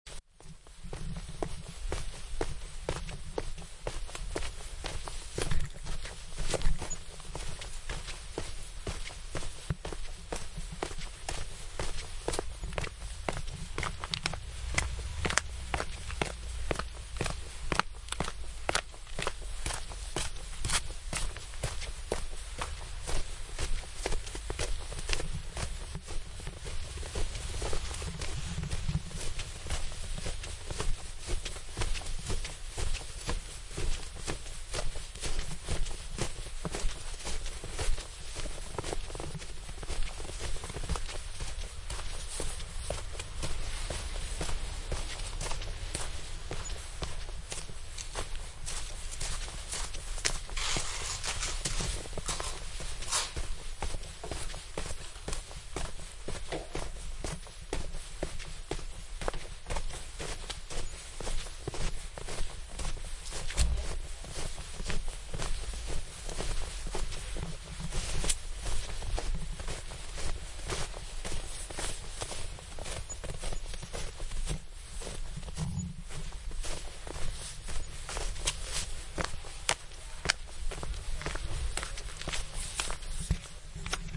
描述：西班牙Aracena附近一条小溪的不同视角。麦克风设置在水流中间，有一个小三角架。M/S立体声
标签： 现场录音 性质 飞溅 冬季
声道立体声